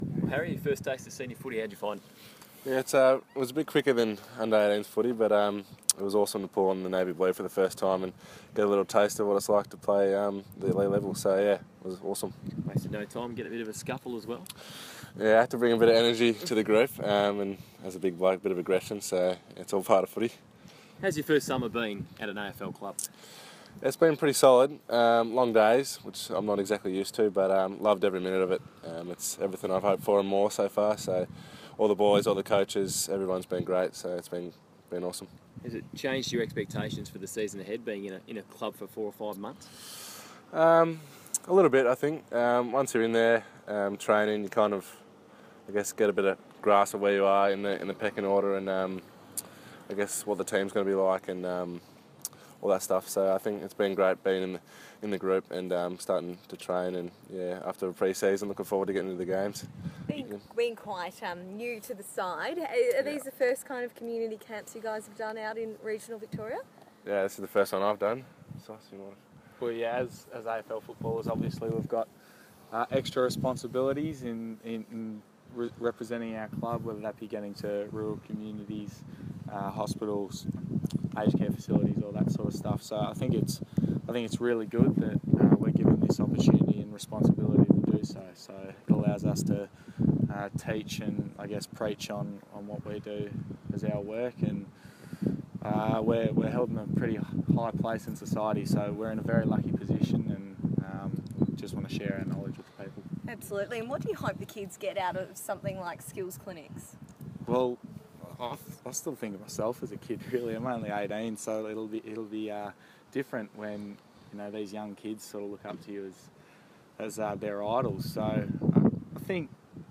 Jack Silvagni and Harry McKay speak to the media during Carlton's 2016 Australia Post Community Camp in Maryborough.